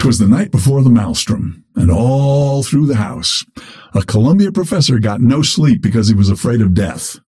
Dynamo voice line - T'was the night before the Maelstrom, and all through the house, a Columbia professor got no sleep because he was afraid of death.